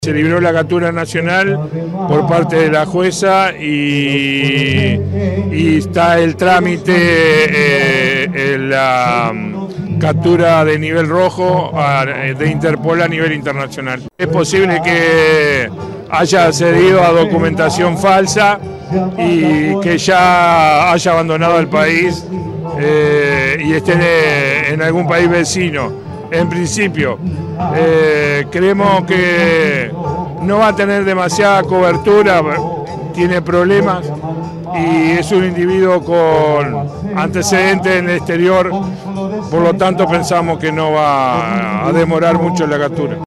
Palabras de Mario Layera